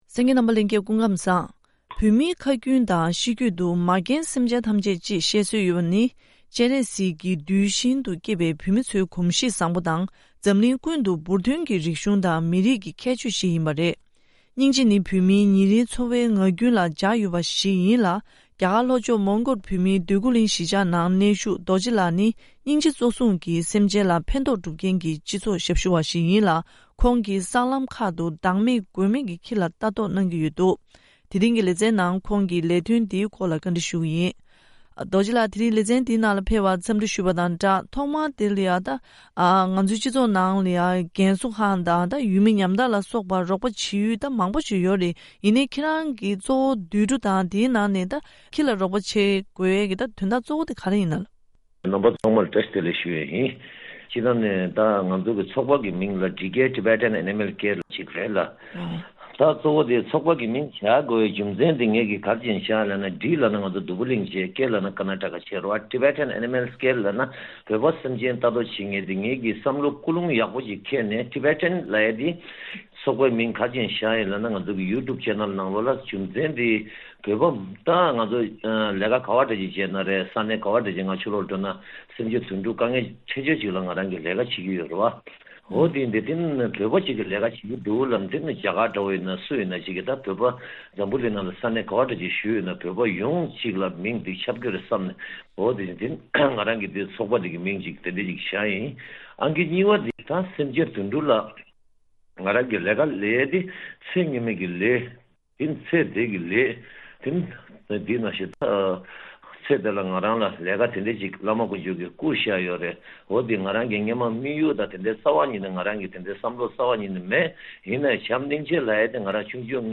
ཐེངས་འདིའི་བཅར་འདྲིའི་ལེ་ཚན